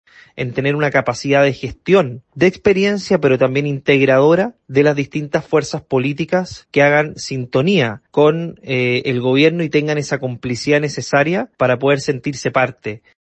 En ese sentido, el diputado Andrés Longton (RN) dijo esperar una combinación entre capacidad de gestión e integración de los partidos.